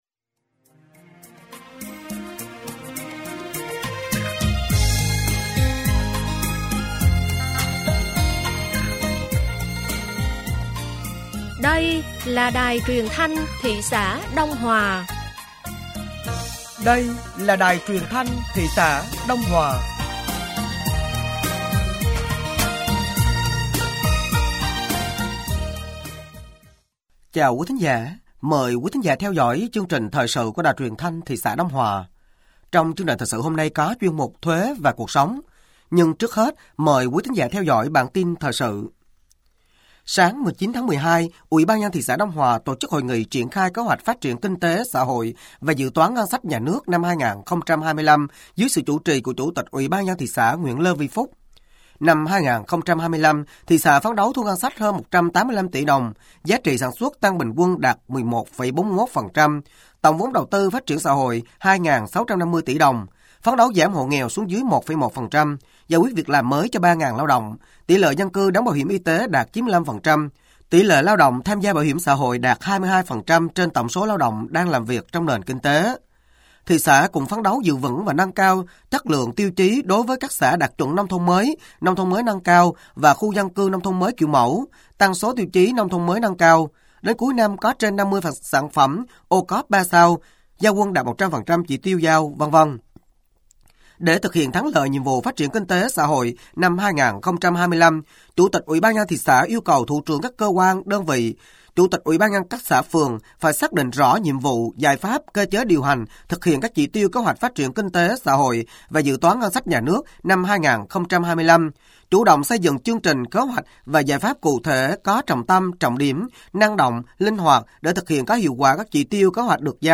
Thời sự tối ngày 19 và sáng ngày 20 tháng 12 năm 2024